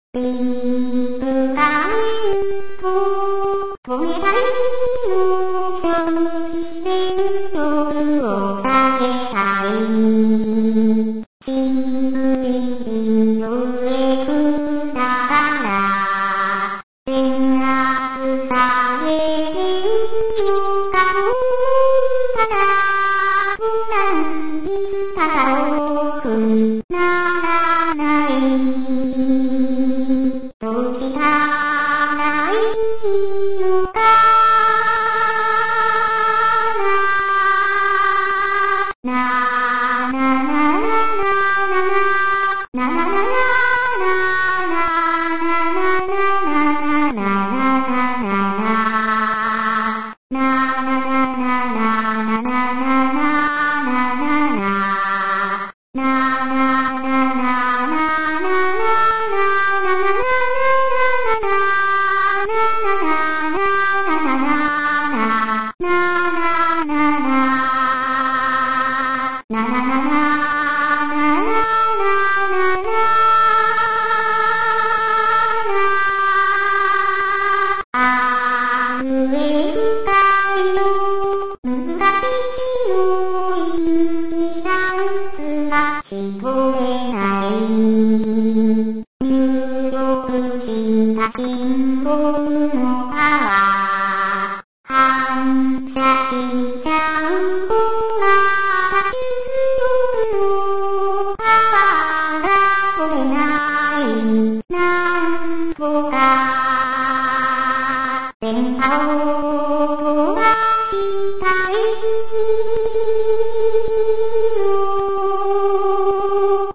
Singing ??